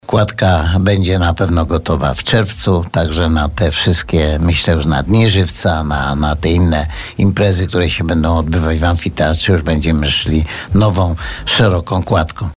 W kwestii terminu jej powstania gospodarz Żywca wypowiadał się dzisiaj na antenie Radia Bielsko.